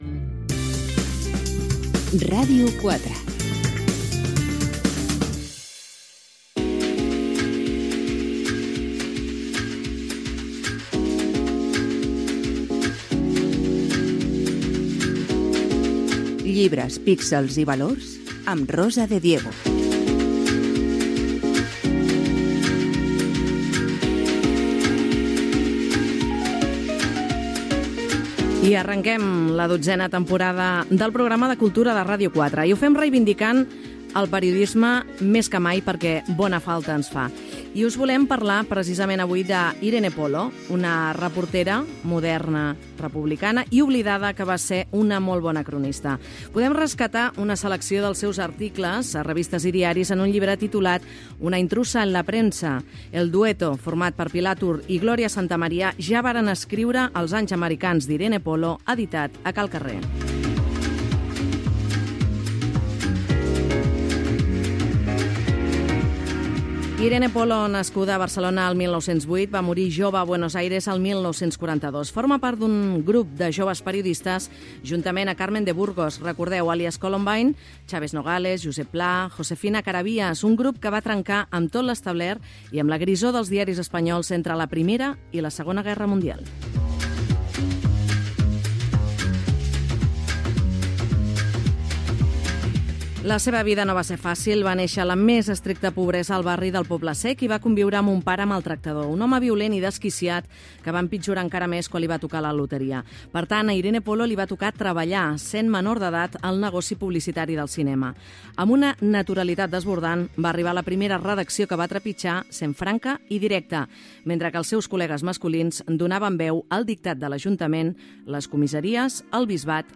Indicatiu. Careta. Presentació del primer programa de 12 temporada del programa. El llibre sobre la periodista Irene Polo.